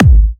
VEC3 Bassdrums Trance 51.wav